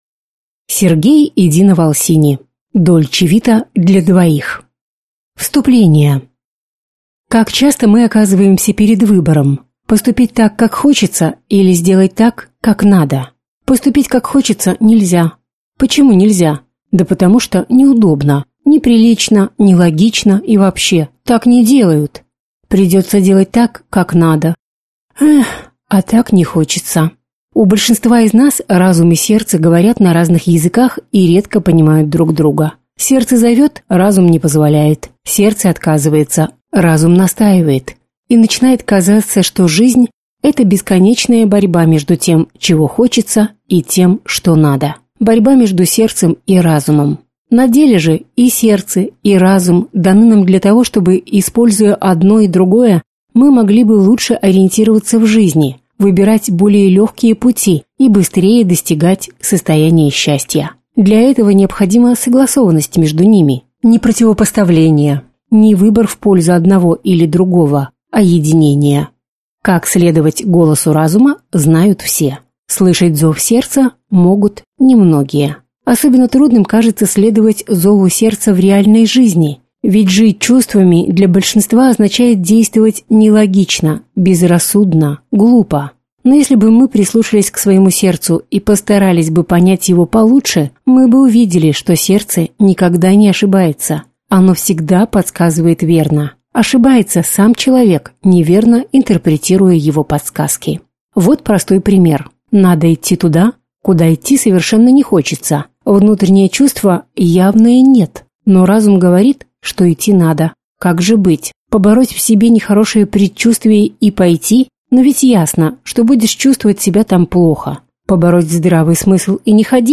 Аудиокнига Дольче Вита для двоих | Библиотека аудиокниг